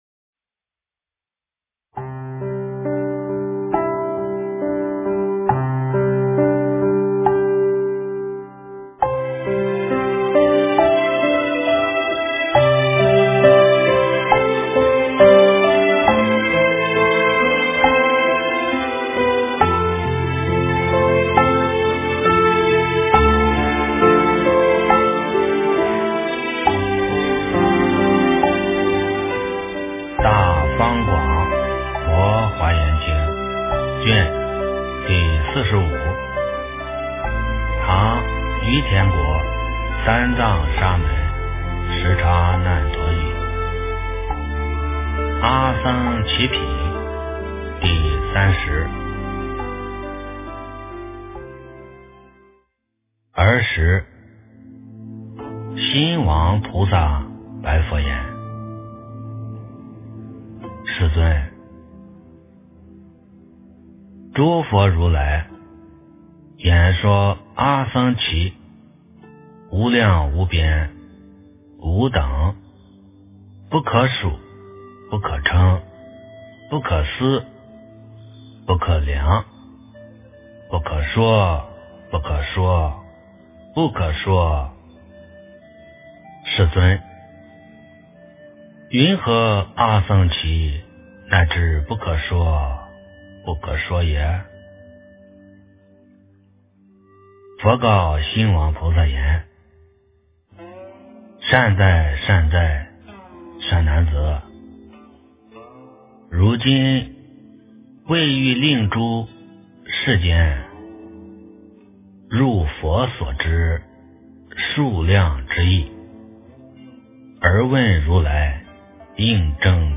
《华严经》45卷 - 诵经 - 云佛论坛